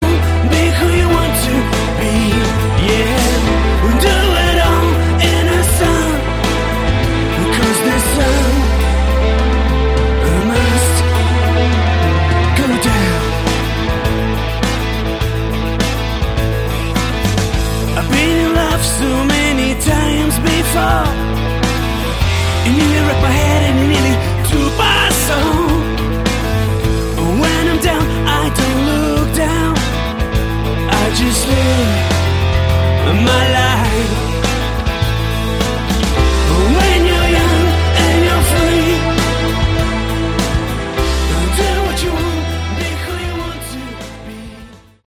Category: Hard Rock
lead and backing vocals, guitar, piano
guitars, backing vocals
drums, percussion
bass